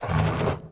Index of /App/sound/monster/ice_snow_dog
walk_1_2.wav